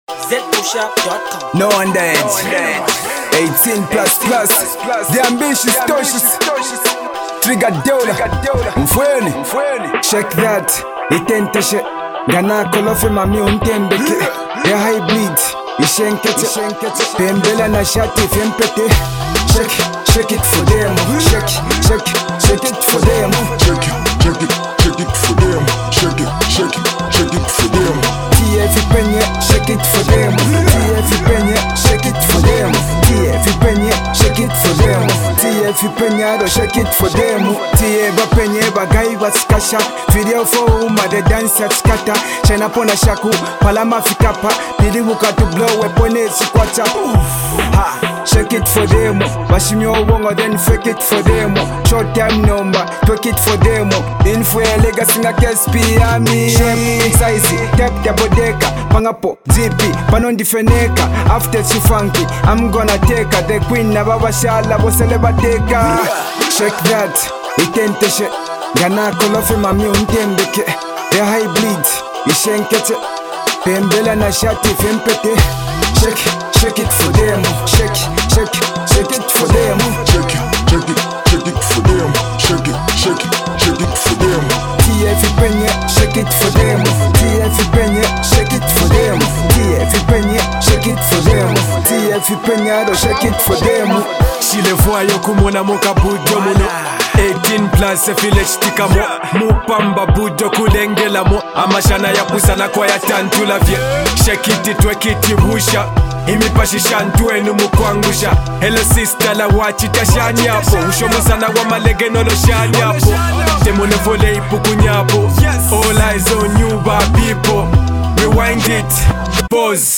dancehall tune